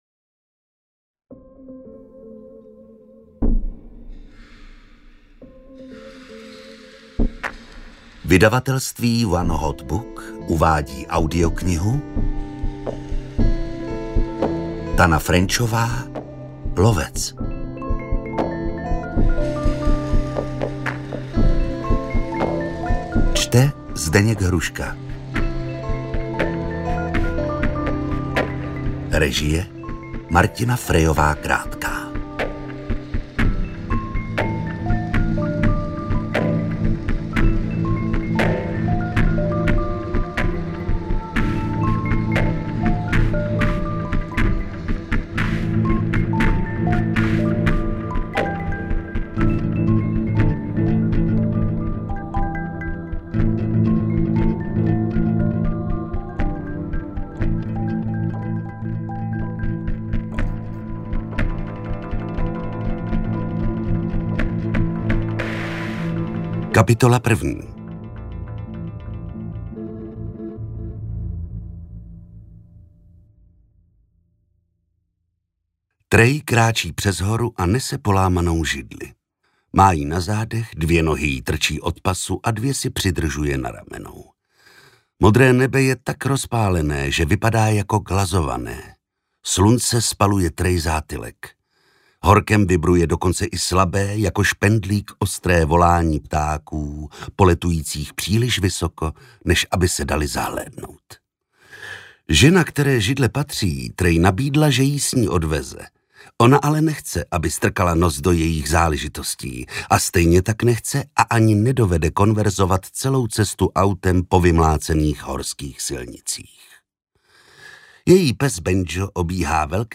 Interpret:  Zdeněk Hruška
AudioKniha ke stažení, 21 x mp3, délka 18 hod. 38 min., velikost 1019,0 MB, česky